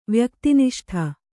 ♪ vyakti niṣṭha